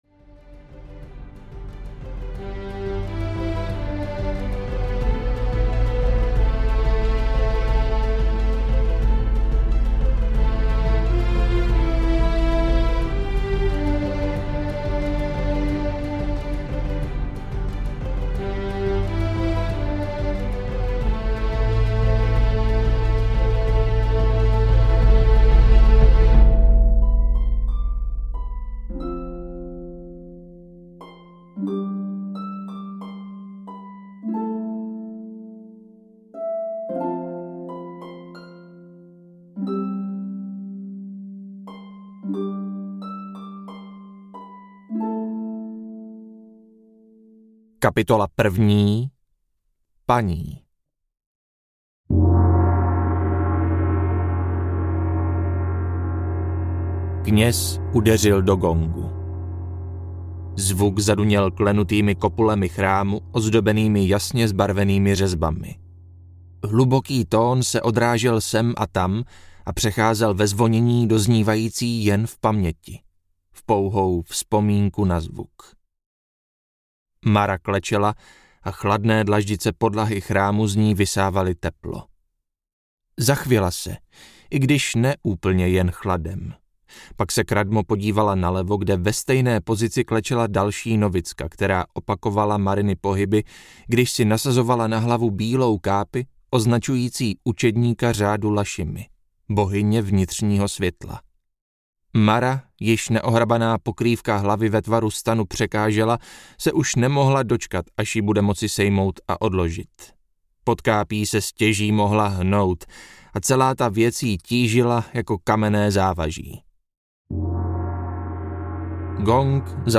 Dcera impéria audiokniha
Ukázka z knihy